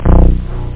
Amiga 8-bit Sampled Voice
1 channel
303-smalldrive.mp3